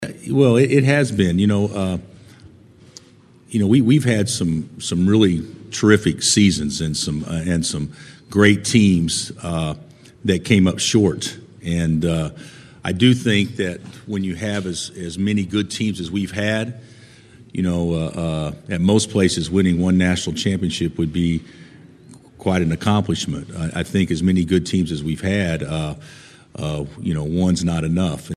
In the presser, he spoke on what it meant to return to the big stage.